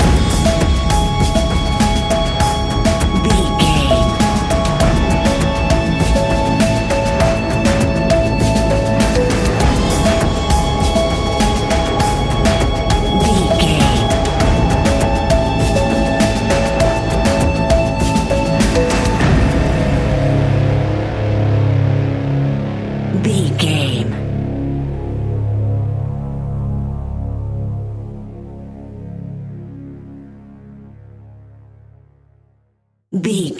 Epic / Action
In-crescendo
Ionian/Major
C♯
dark ambient
synths